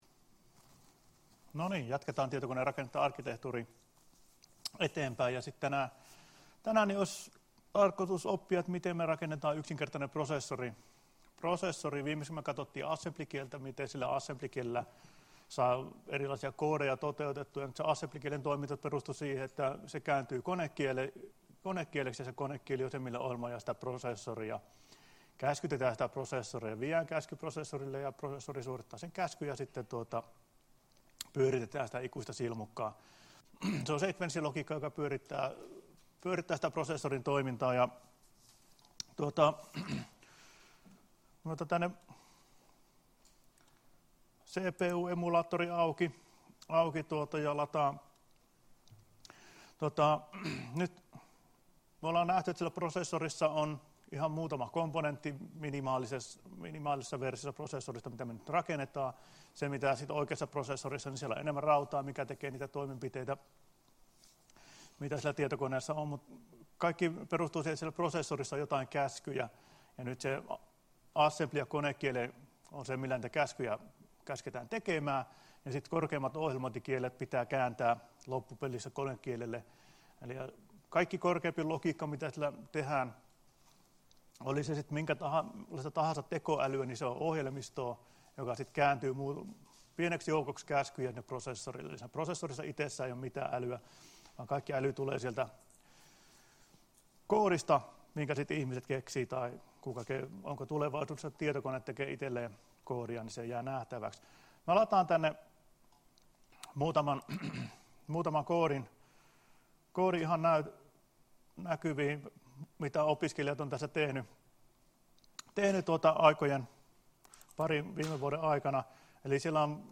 Luento 4.12.2017 — Moniviestin